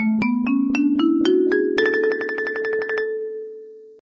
marimba_scale_up_2.ogg